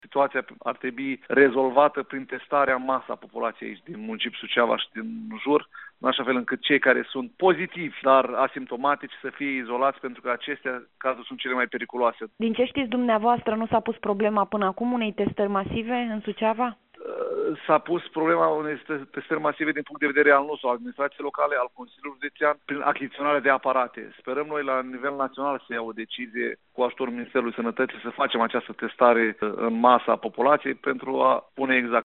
31mar-10-Viceprimar-SV-e-nevoie-de-testare-masiva-in-judet.mp3